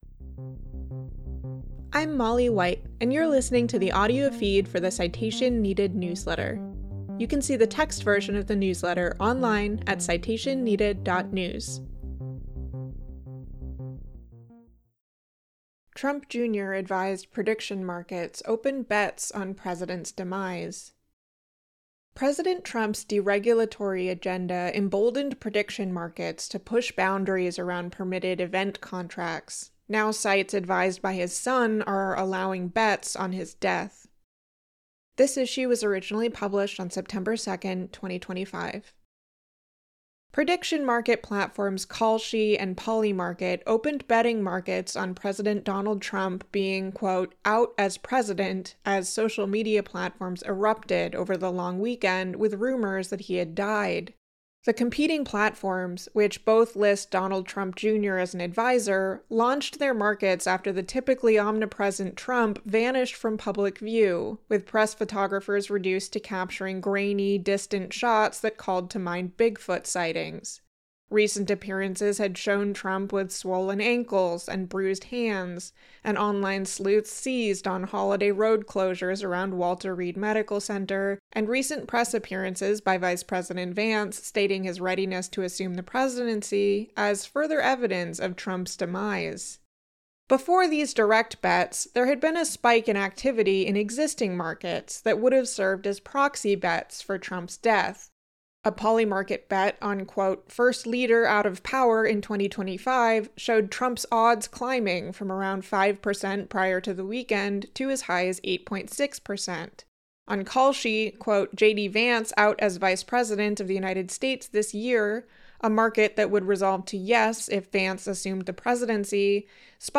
Listen to me read this post here (not an AI-generated voice!), subscribe to the feed in your podcast app, or download the recording for later.